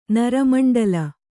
♪ nara maṇḍala